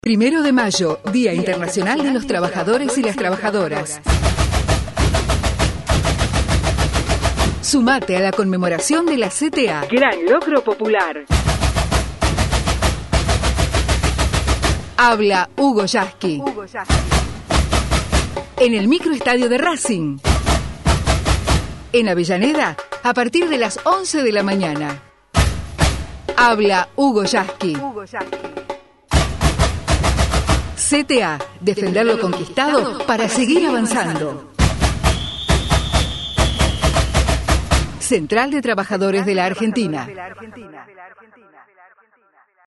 SPOT radial - 1º DE MAYO - ACTO CTA EN RACING